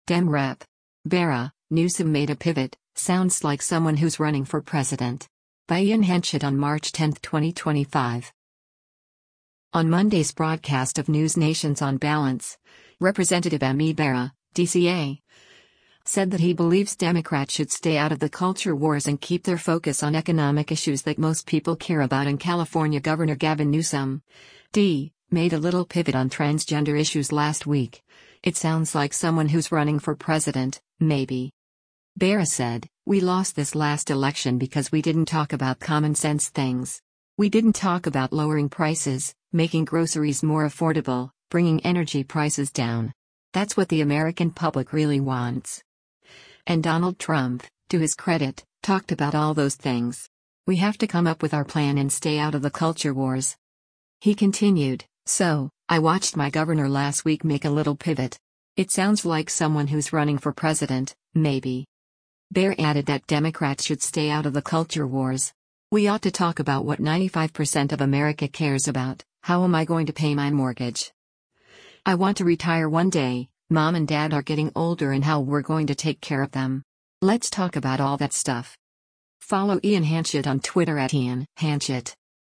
On Monday’s broadcast of NewsNation’s “On Balance,” Rep. Ami Bera (D-CA) said that he believes Democrats should “stay out of the culture wars” and keep their focus on economic issues that most people care about and California Gov. Gavin Newsom (D) made “a little pivot” on transgender issues last week, “It sounds like someone who’s running for president, maybe.”